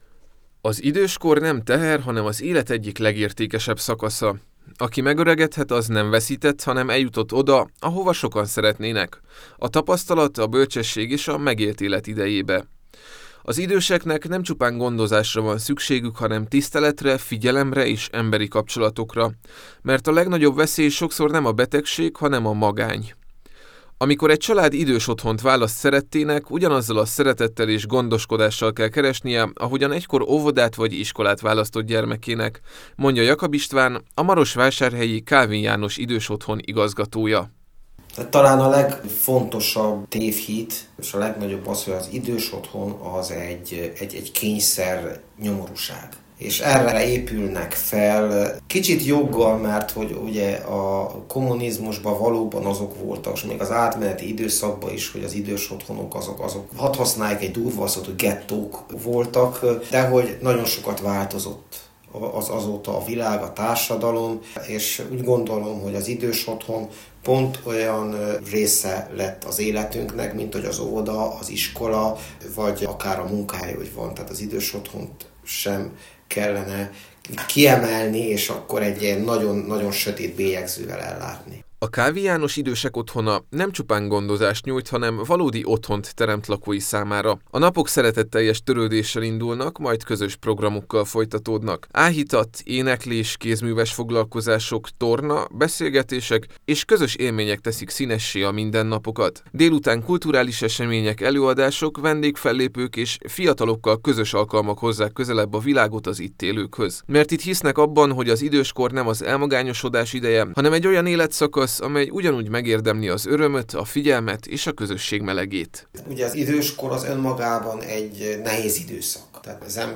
Hogy milyen a mindennapi élet, milyen emberi történetek húzódnak a falak mögött, és mit jelent ma a gondoskodás, annak jártunk utána a marosvásárhelyi Kálvin János Idősek Otthona lakói és munkatársai között.